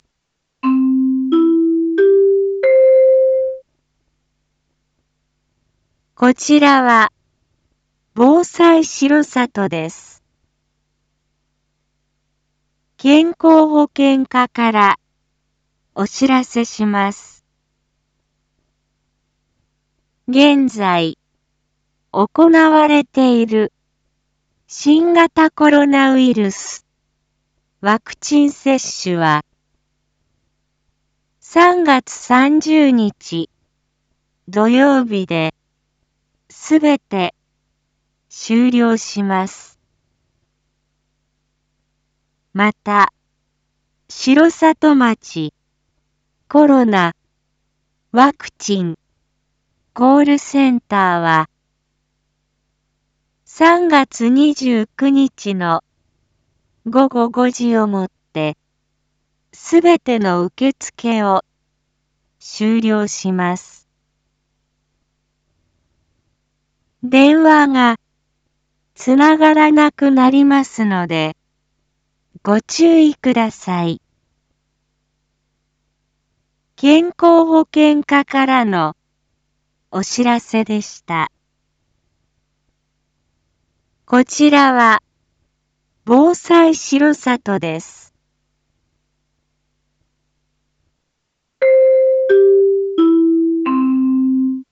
Back Home 一般放送情報 音声放送 再生 一般放送情報 登録日時：2024-03-26 19:01:36 タイトル：新型コロナウイルスワクチン接種の終了について インフォメーション：こちらは、防災しろさとです。